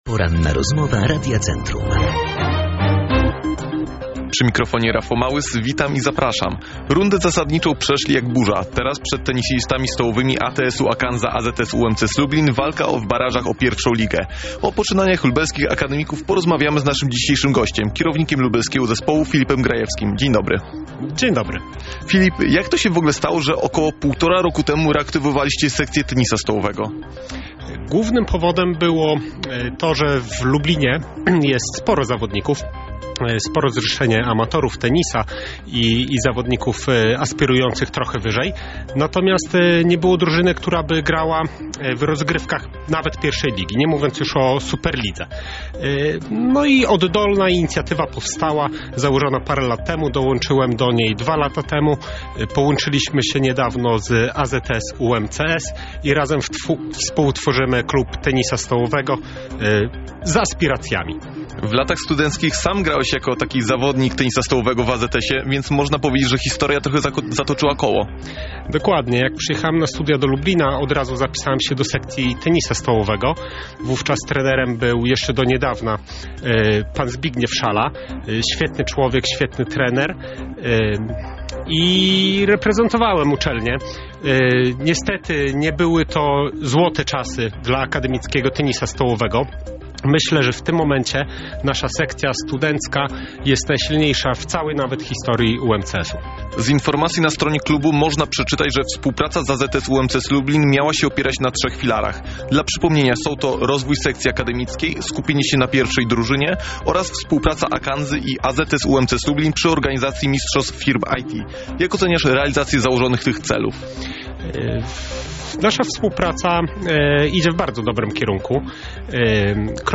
Teraz akademicy szlifują swoją formę przed kolejną rundą rywalizacji i właśnie między innymi o tym porozmawialiśmy na antenie naszego radia.
Rozmowa-po-edycji-1.mp3